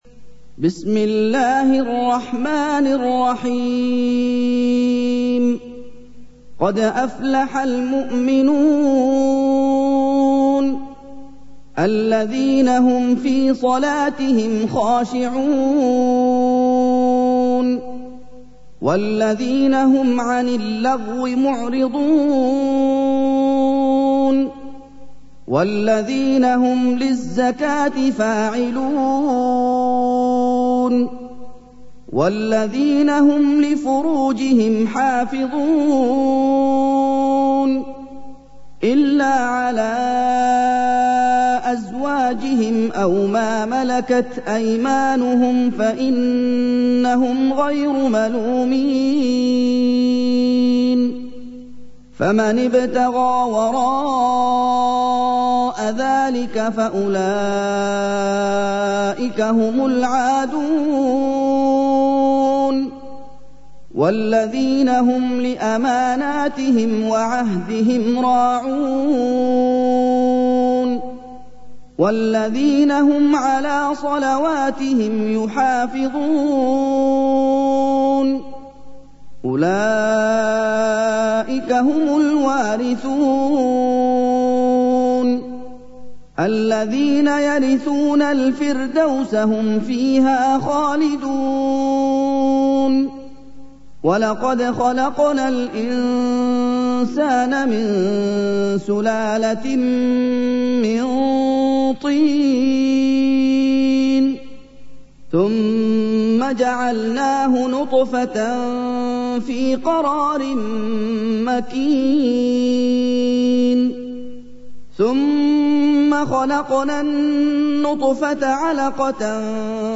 سُورَةُ المُؤۡمِنُونَ بصوت الشيخ محمد ايوب